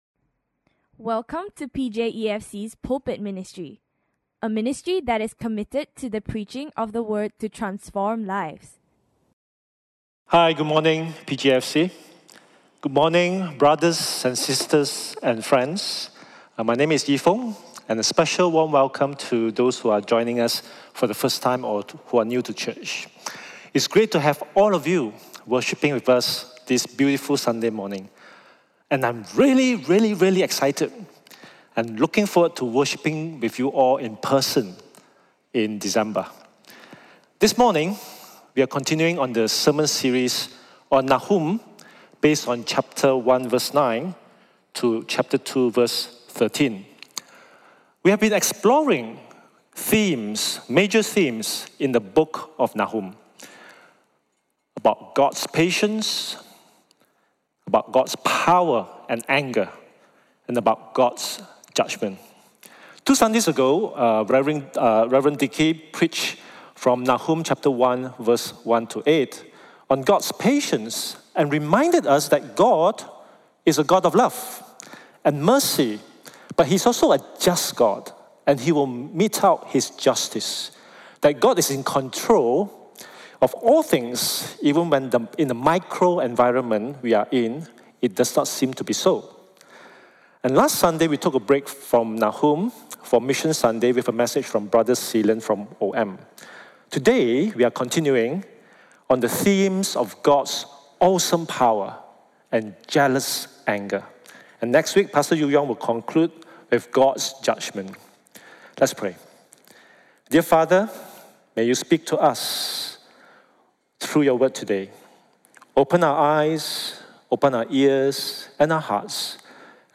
Listen to Sermon Only